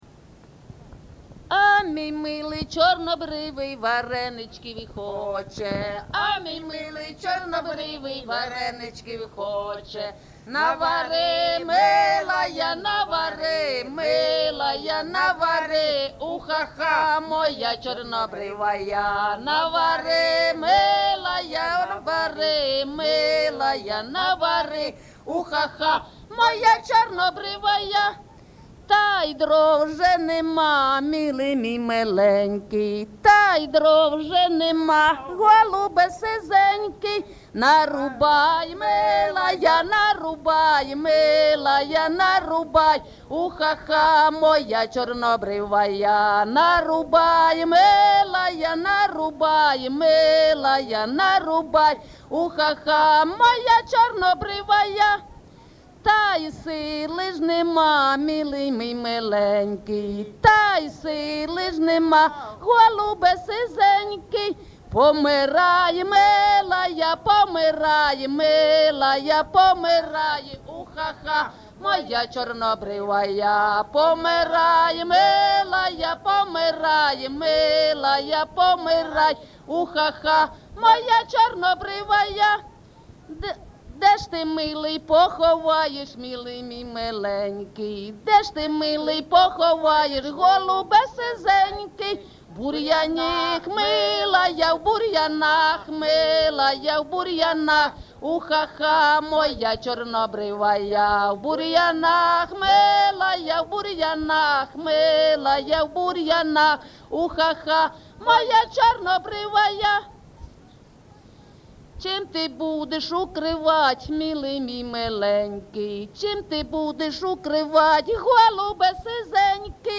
ЖанрПісні з особистого та родинного життя, Жартівливі
Місце записус-ще Калинівка, Бахмутський район, Донецька обл., Україна, Слобожанщина